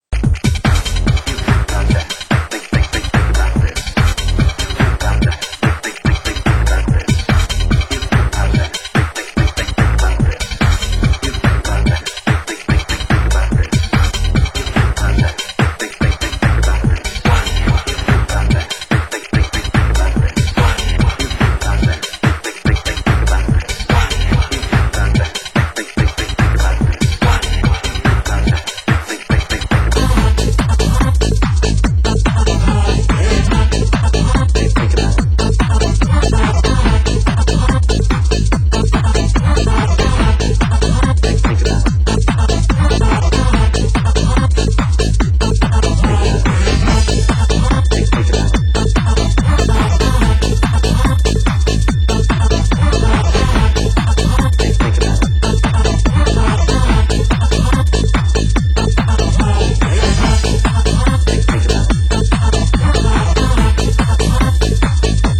Genre Hard House